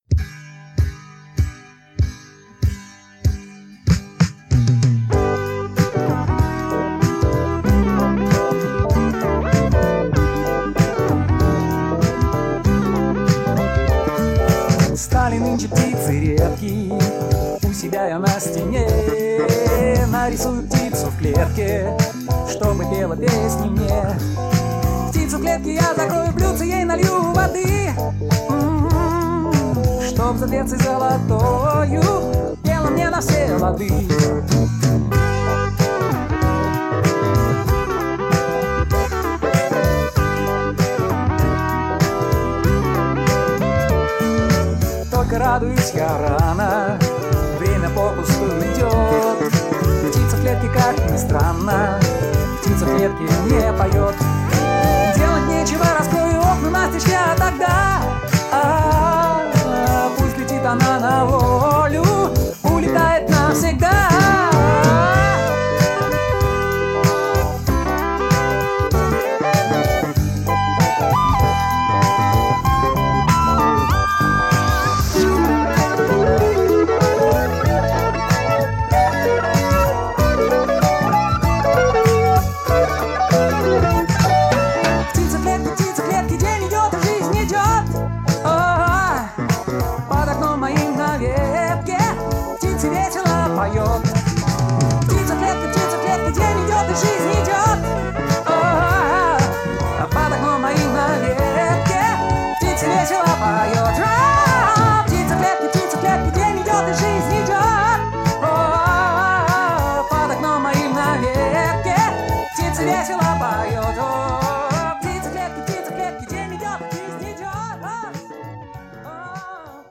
ударные